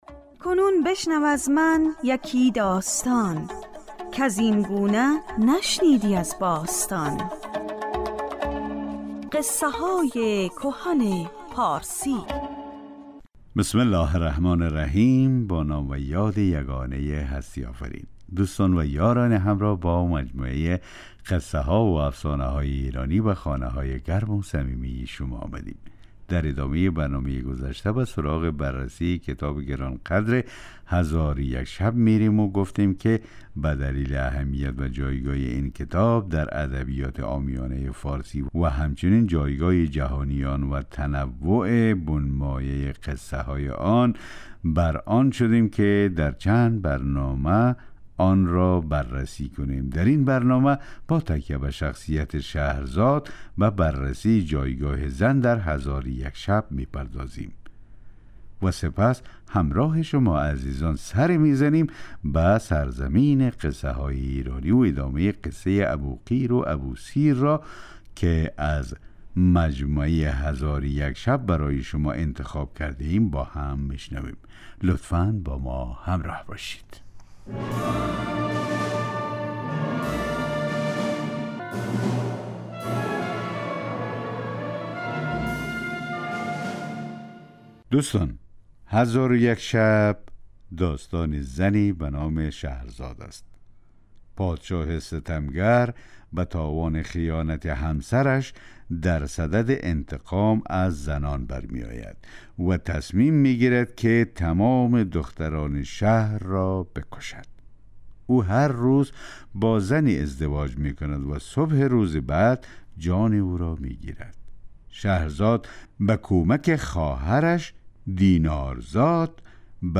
برنامه قصه های کهن پارسی جمعه ها ساعت 12:40 دقیقه به وقت ایران پخش می شود. در بخش اول این برنامه به ادبیات پارسی پرداخته می شود و در بخش دوم یکی از داستان های هزار و یک شب روایت می شود.